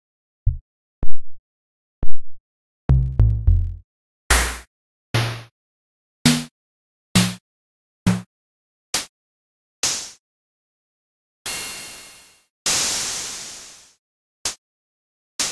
Drums
drums.ogg